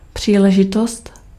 Ääntäminen
Synonyymit možnost Ääntäminen Tuntematon aksentti: IPA: /pr̝̊iːlɛʒɪtɔst/ Haettu sana löytyi näillä lähdekielillä: tšekki Käännös Ääninäyte Substantiivit 1. occasion US 2. opportunity US 3. chance RP NZ US Aus UK Suku: f .